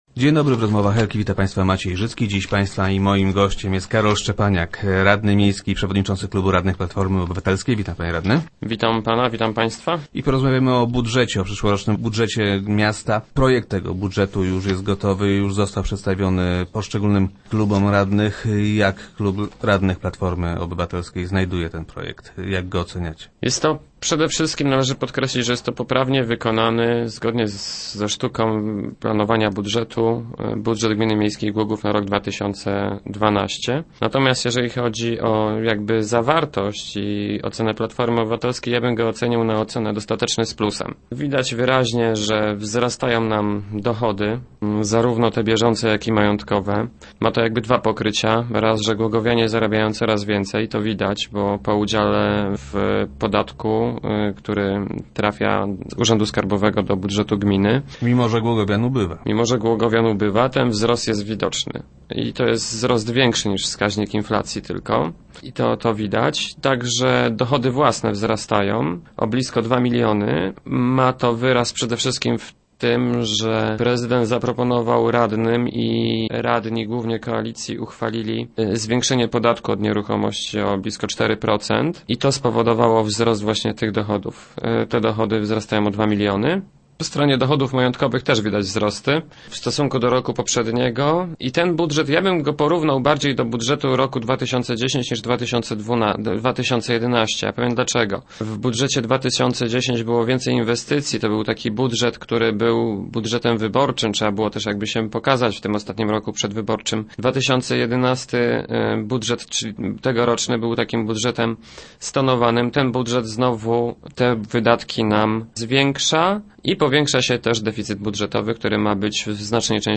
Gościem Rozmów Elki był Karol Szczepaniak, przewodniczący klubu radnych Platformy Obywatelskiej.